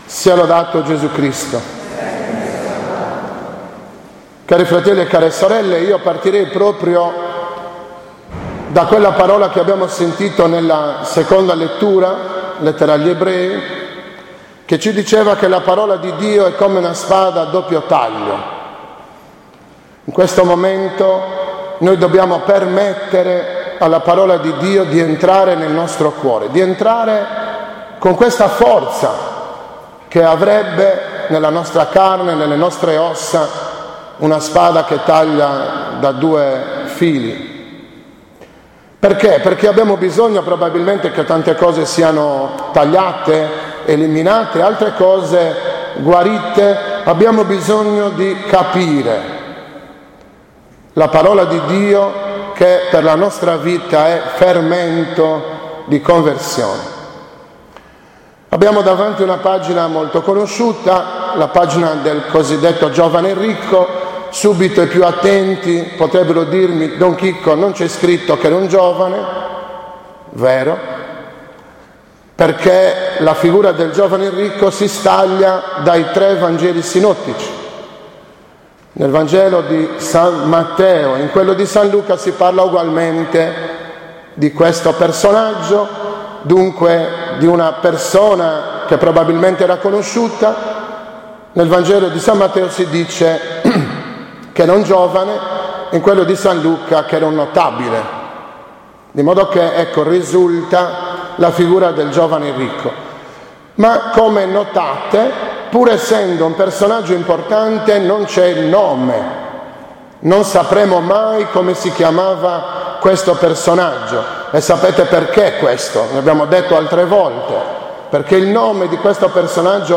11.10.2015 – OMELIA DELLA XXVIII DOMENICA DEL TEMPO ORDINARIO